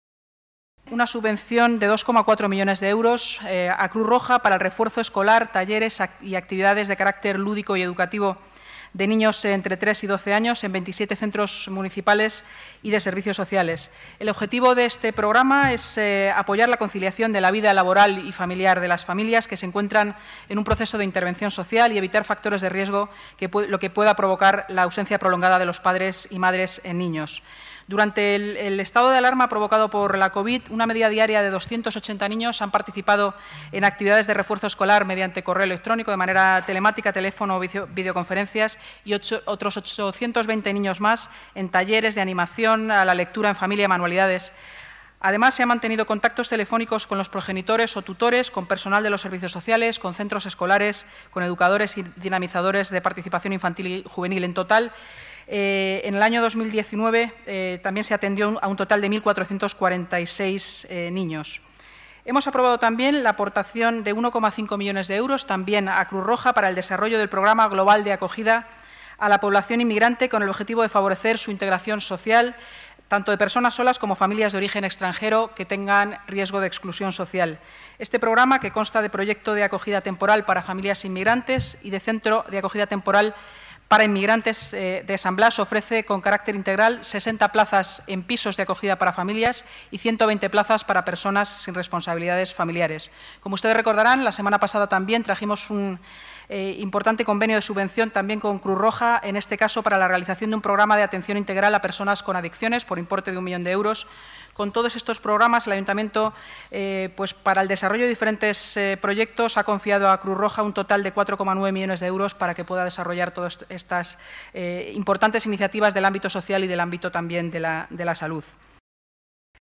Nueva ventana:Declaraciones de Inmaculada Sanz, portavoz municipal, sobre la subvención concedida a Cruz Roja